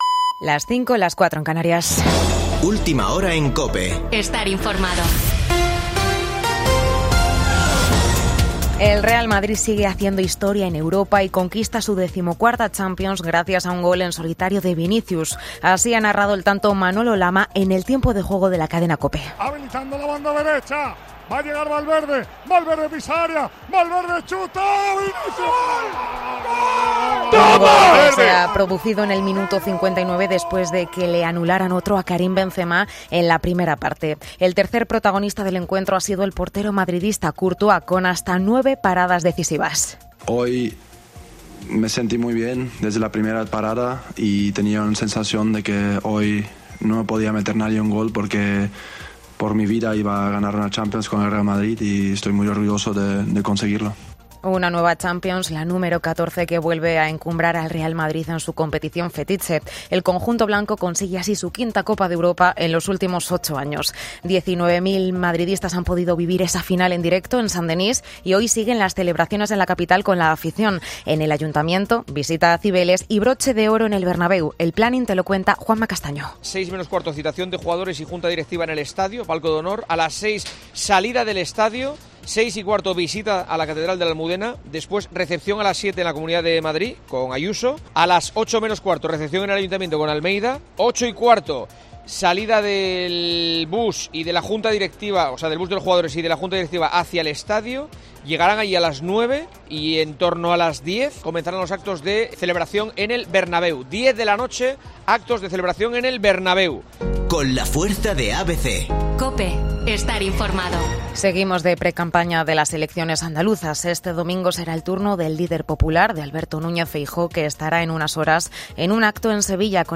Boletín de noticias de COPE del 29 de mayo de 2022 a las 05.00 horas